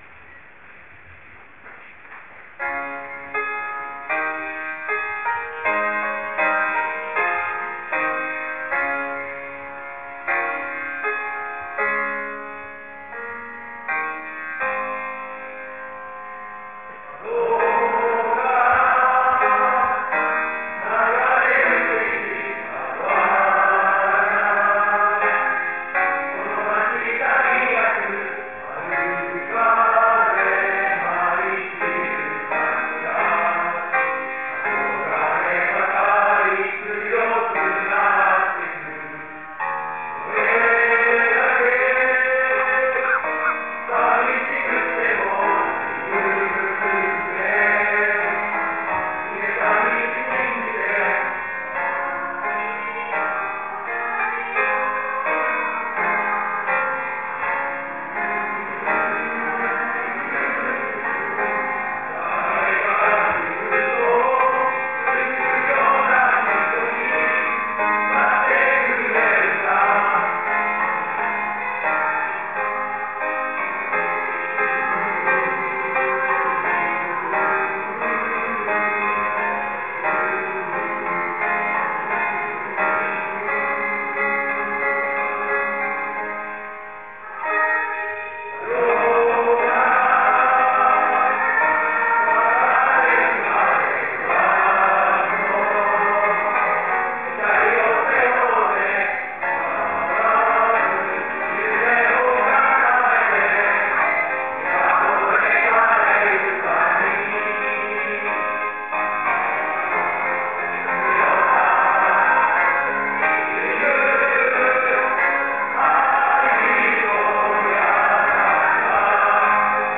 ◇ 卒業式 フォトログ ４／４ ◇
卒業生の合唱「遥か」（ここをクリックしてください）。 その歌声は、体育館に響き渡るとともに、その姿、また、三部合唱のハーモニーは、数々の想い出とともに、卒業生自身の、そして、保護者、在校生、教職員の胸にもしっかりと刻み込まれたことでしょう。
※ 録音位置の関係で、予行の時の合唱の方がきれいに録音できました。